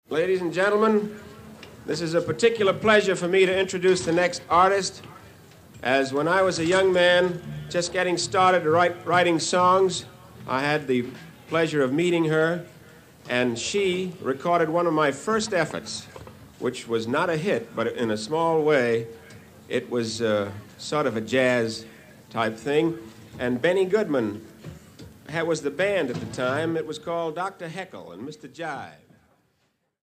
CD2の(12)-(20)はボーナス･トラックで、1957年7月、ニューポート･ジャズ祭でのステージの模様を収録。